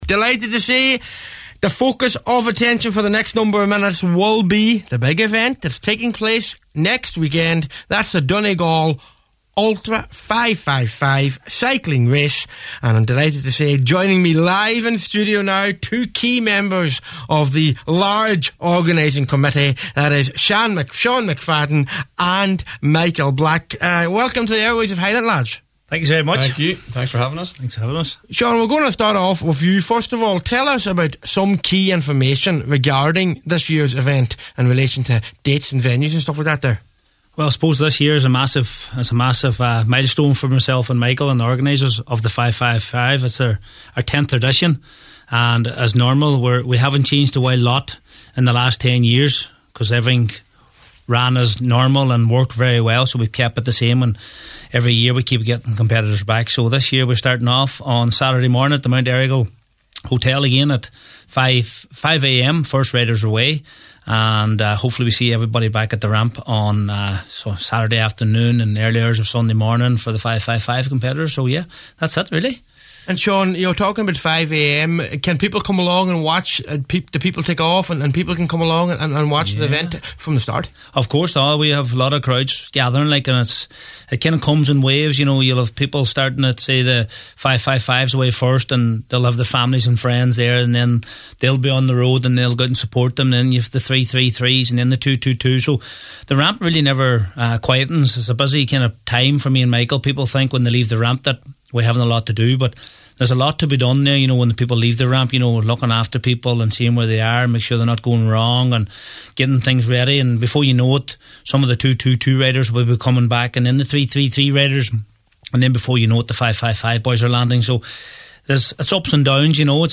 in studio at Highland Radio this evening